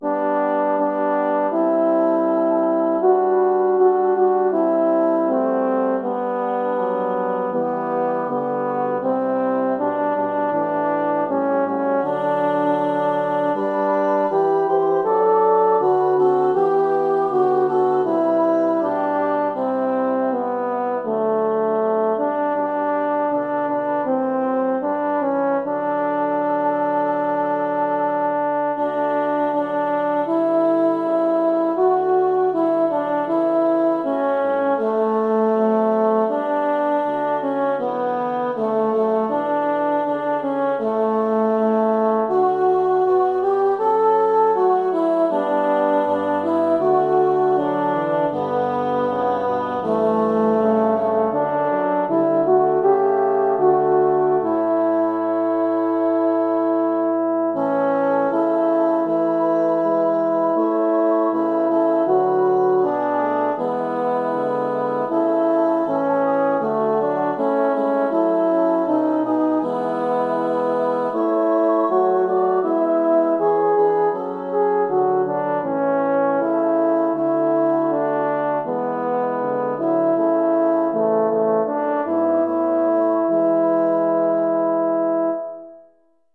jordens_gud_alt.mp3